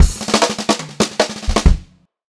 144SPFILL1-R.wav